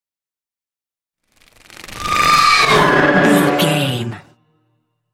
Horror whoosh large
Sound Effects
In-crescendo
Atonal
ominous
eerie